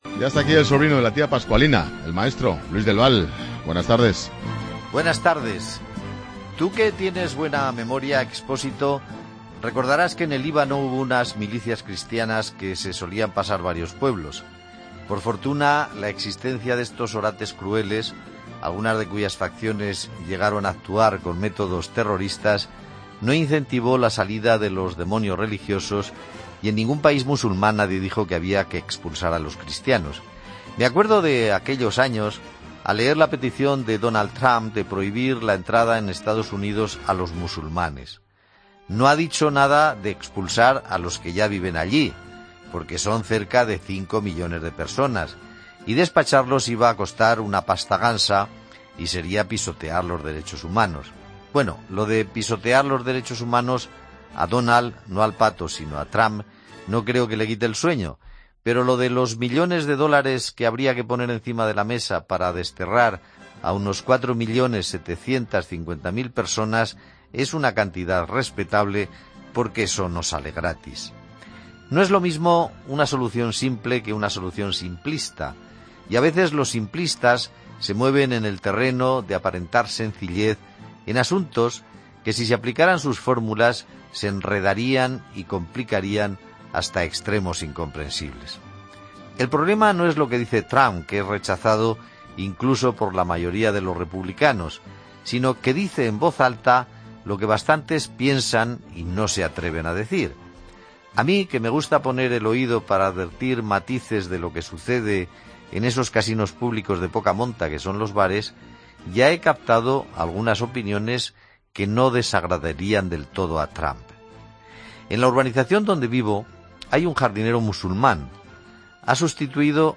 Luis del Val dedica su comentario a las palabras de Donald Trump quien sugiere que se deniegue la entrada a los musulmanes a EEUU debido a la amenaza terrorista, así como a comentarios xenófobos que han surgido a raíz de los últimos atentados.